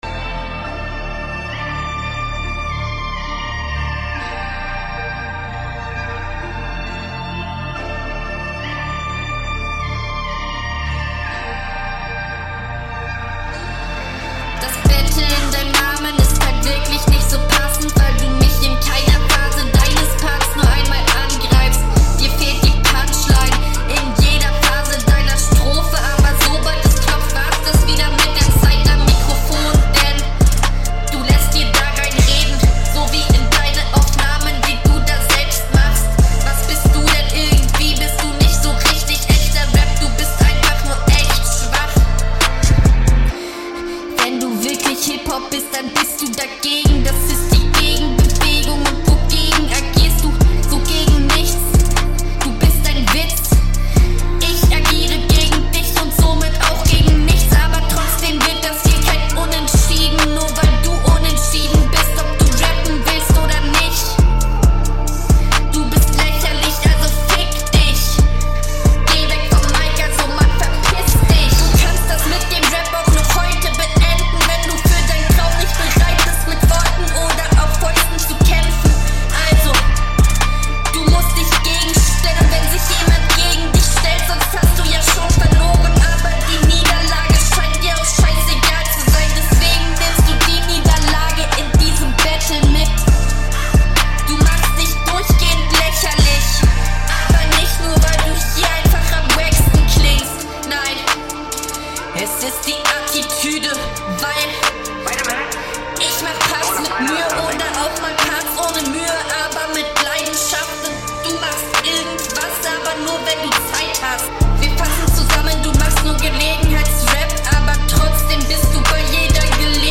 Wirklich "genießen" kann ich Deine Stimme jetzt nicht, was wohl am MIC liegt oder an …